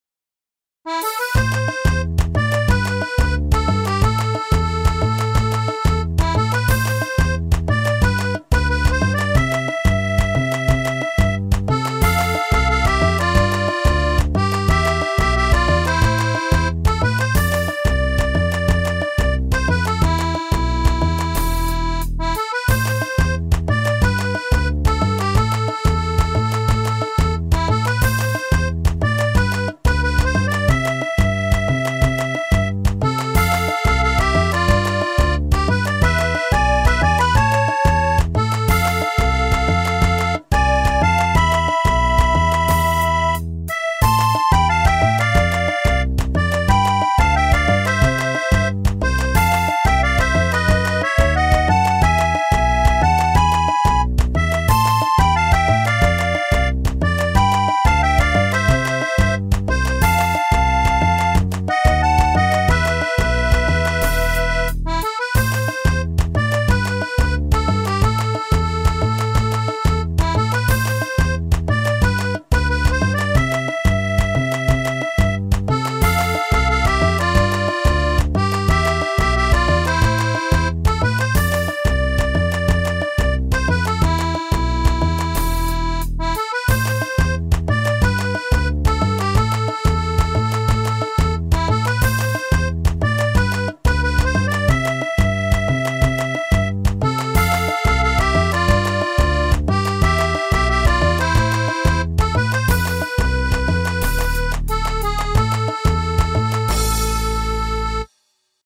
BGM
ロング暗い民族